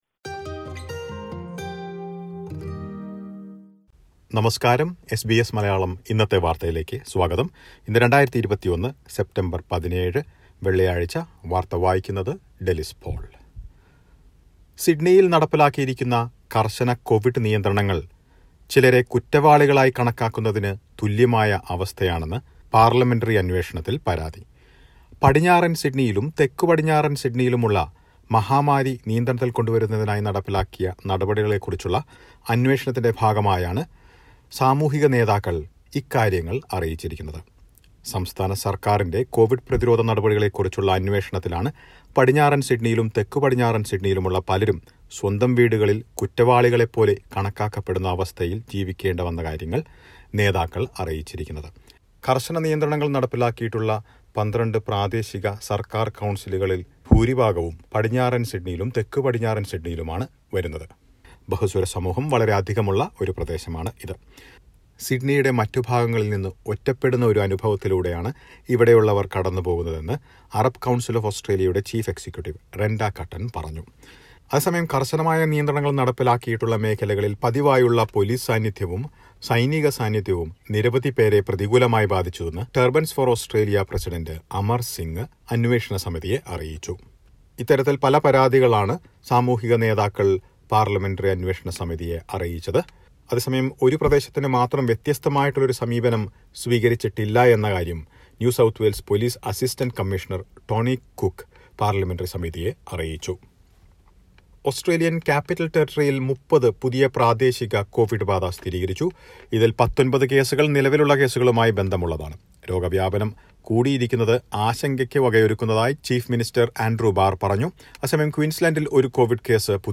2021 സെപ്റ്റംബർ 17ലെ ഓസ്ട്രേലിയയിലെ ഏറ്റവും പ്രധാന വാർത്തകൾ കേൾക്കാം...